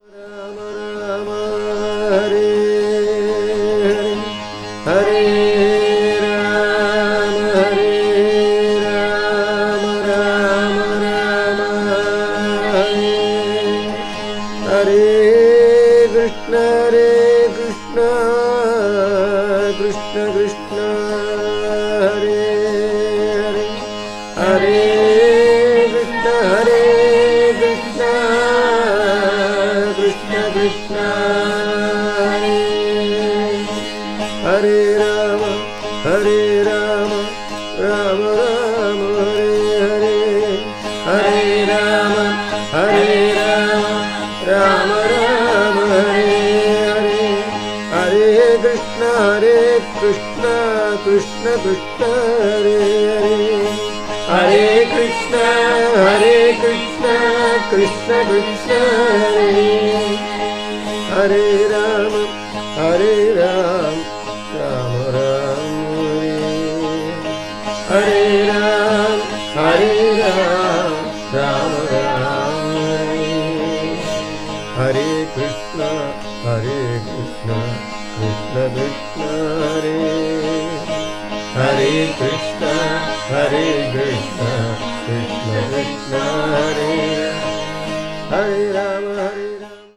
media : EX/EX(わずかにチリノイズが入る箇所あり)
bhajan   bhakti   classical   ethnic music   india   traditional